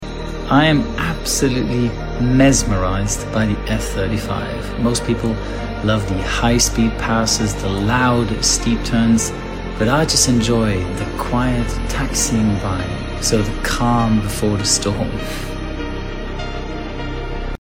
But I often prefer the slow taxis when she seems so peaceful.😌 Wishing You All The Best